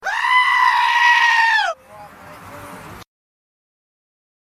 Sound Effects
Screaming Sheep 2